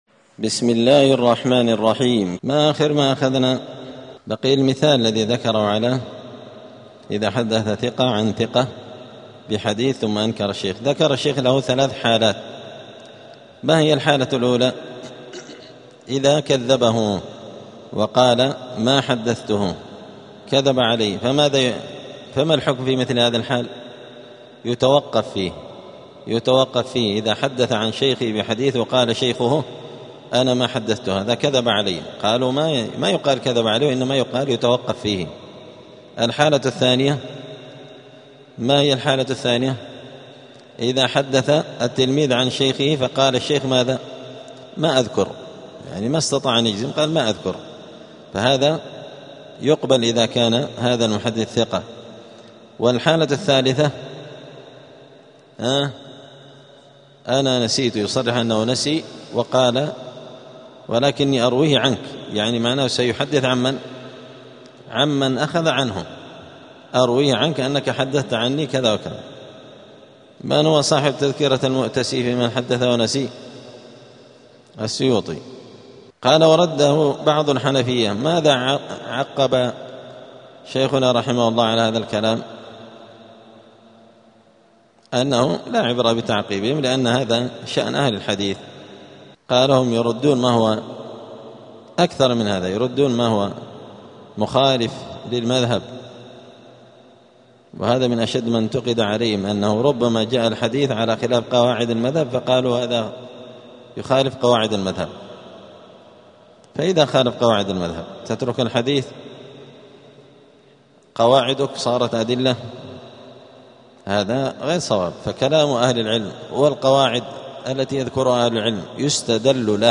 دار الحديث السلفية بمسجد الفرقان قشن المهرة اليمن
الدروس اليومية